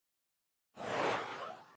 sent.mp3